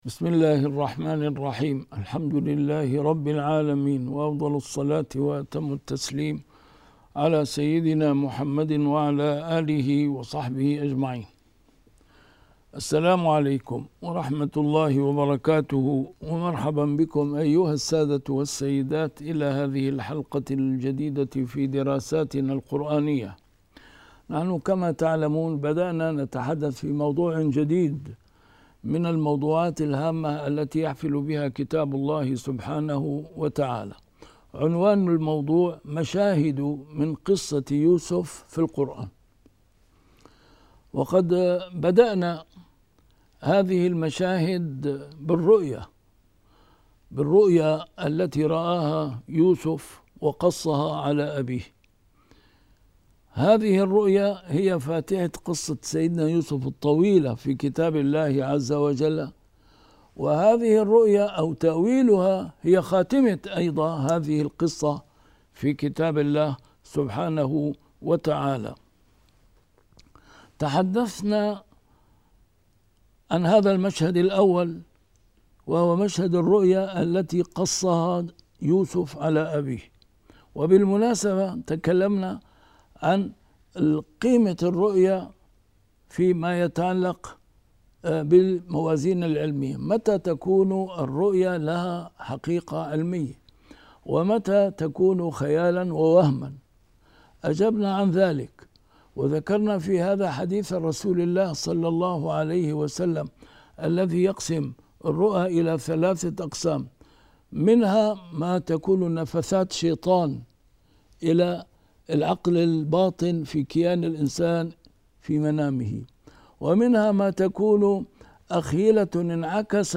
A MARTYR SCHOLAR: IMAM MUHAMMAD SAEED RAMADAN AL-BOUTI - الدروس العلمية - مشاهد من قصة سيدنا يوسف في القرآن الكريم - 2- إلقاء سيدنا يوسف في الجبّ